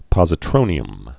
(pŏzĭ-trōnē-əm)